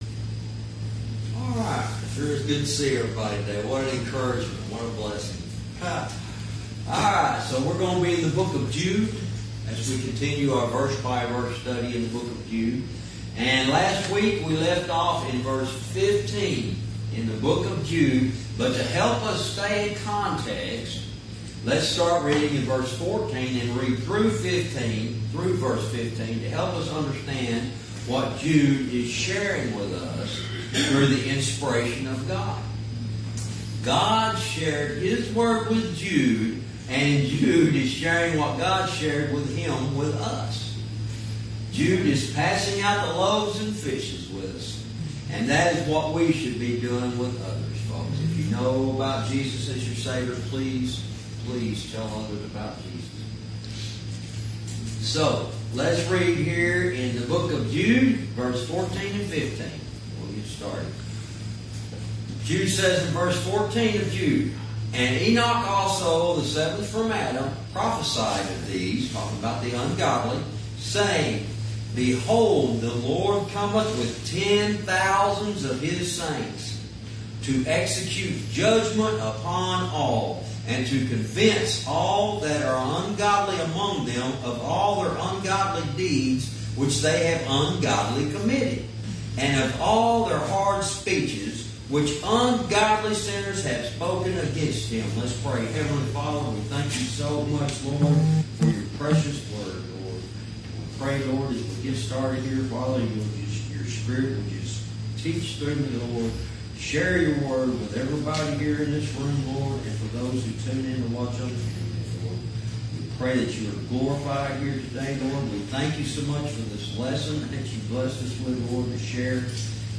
Verse by verse teaching - Jude lesson 65 verse 15